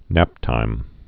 (năptīm)